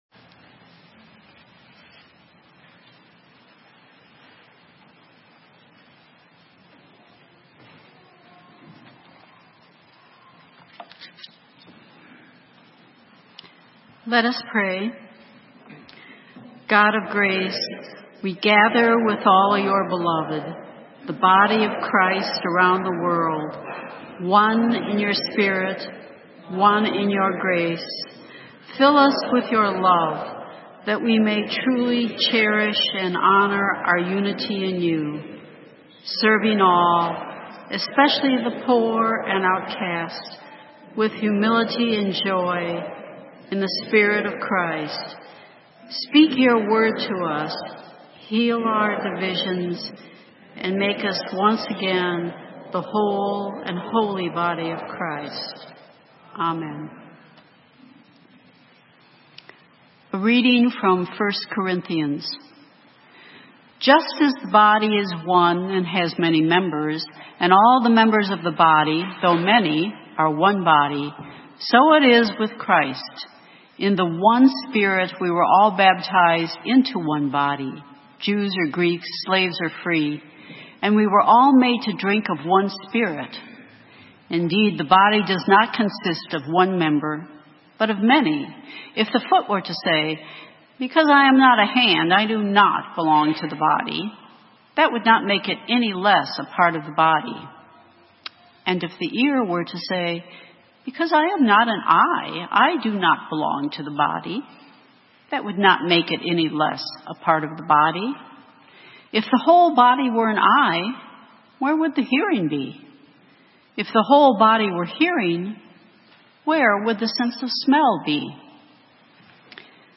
Sermon:To such as these - St. Matthew's UMC
October 7, 2018 — World Communion Sunday